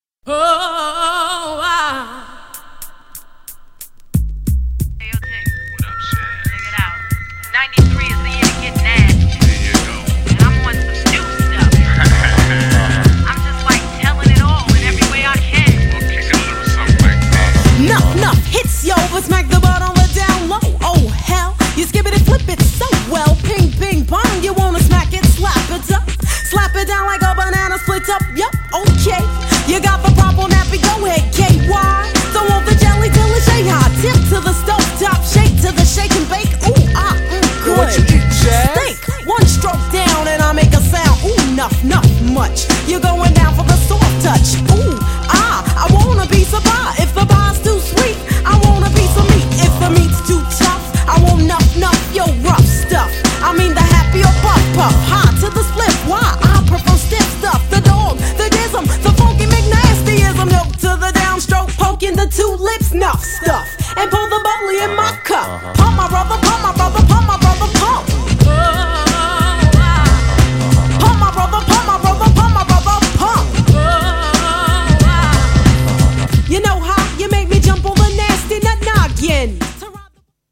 90'sな男気満々なフィーメールラッパー!!
GENRE Hip Hop
BPM 86〜90BPM